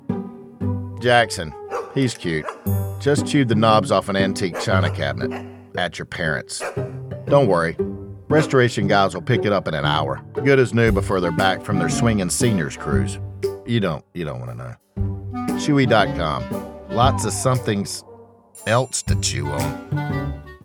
Male
English (North American)
Adult (30-50), Older Sound (50+)
Flat
Radio Commercials
Conversational